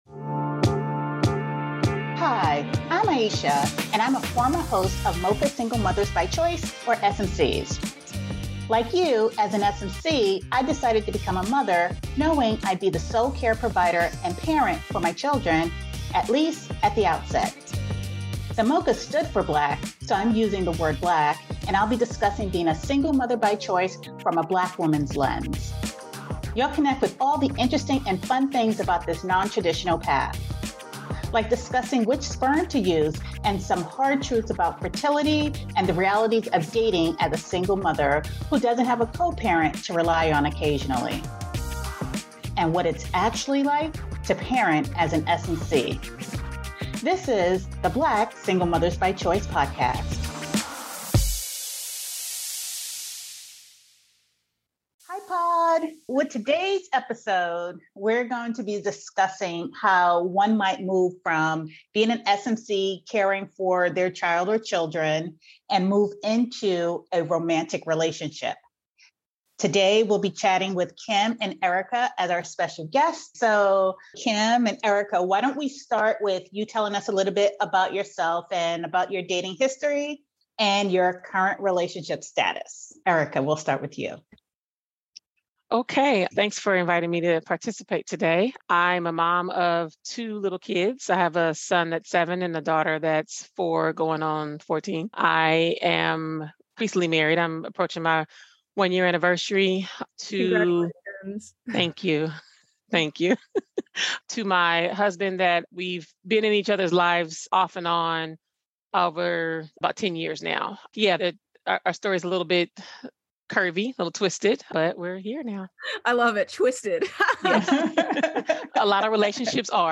our special guests